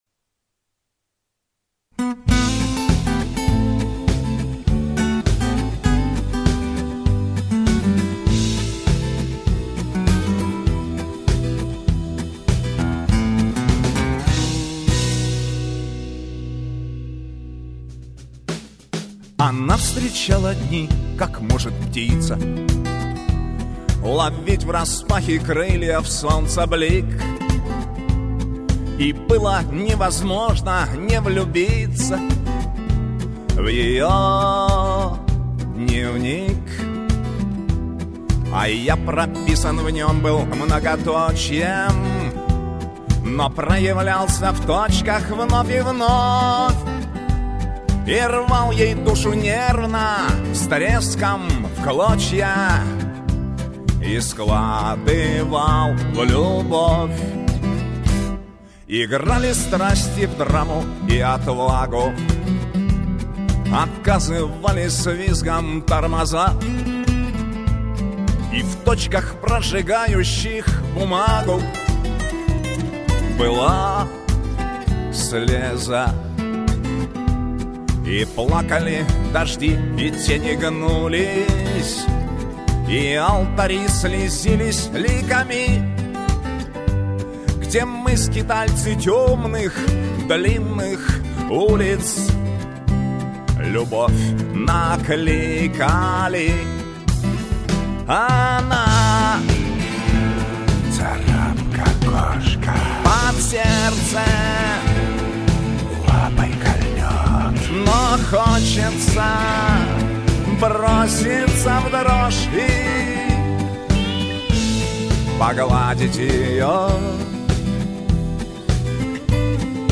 шансонье